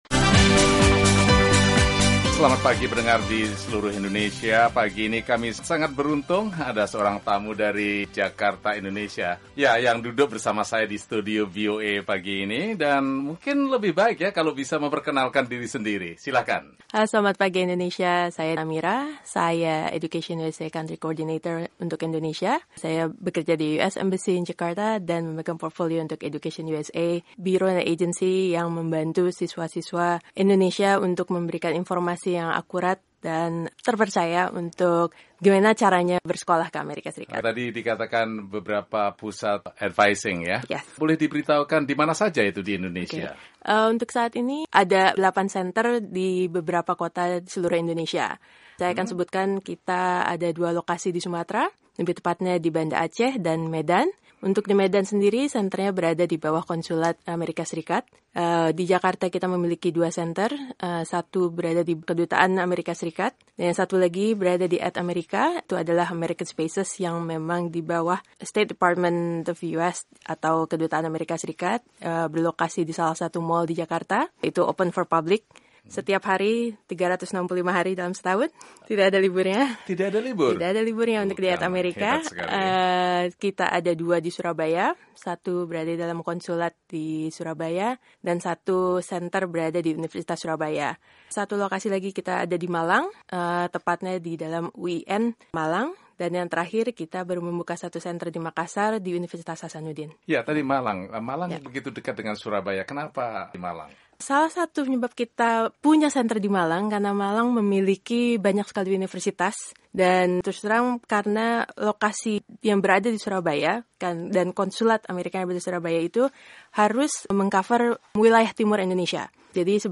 bincang-bincang